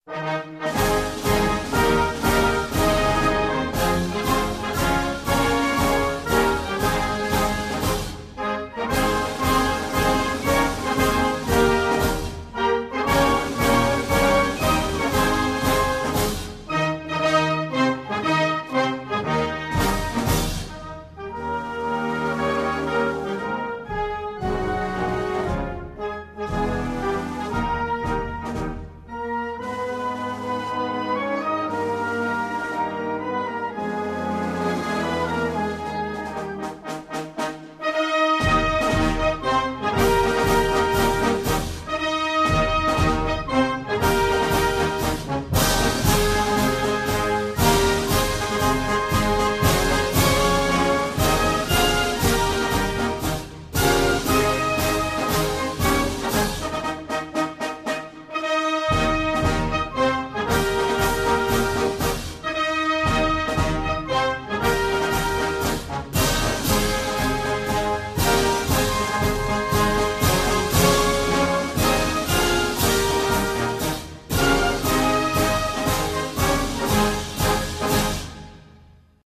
Na spletu poišči himne Avstralije, Nove Zelandije in Francoske Polinezije.